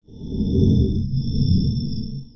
Фантастический бип (Bip Sound FX) Скачать звук music_note Sci-fi , Фантастика save_as 26.8 Кб schedule 0:01:00 10 0 Теги: mp3 , Sci-Fi , бип , звук , звуковые эффекты , короткие звуки , космос , Пип , Сигнал , Фантастика